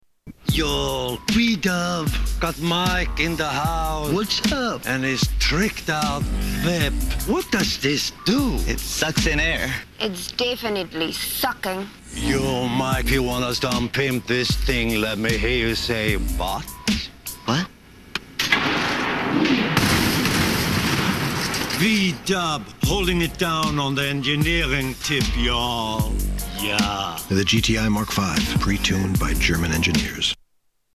Volkswagen and the Weird German Guy
Tags: Television Awful Commericals Commercials Bad Commercial Media